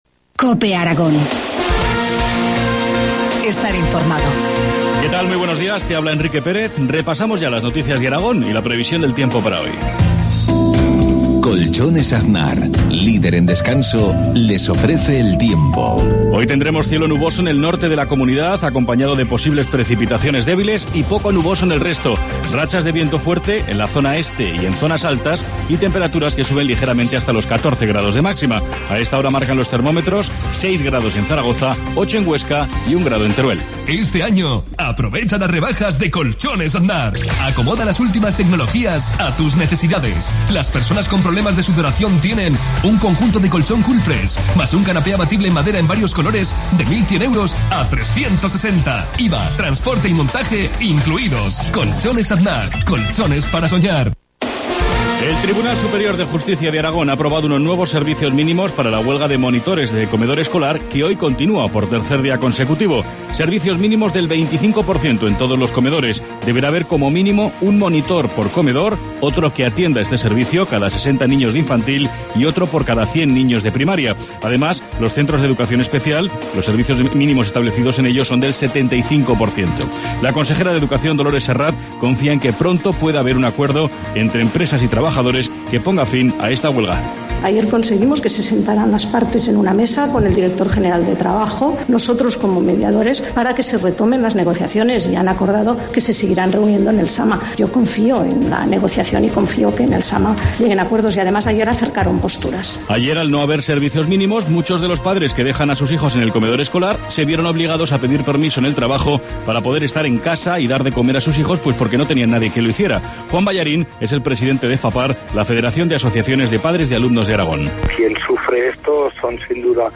Informativo matinal, jueves 10 de enero, 7.53 horas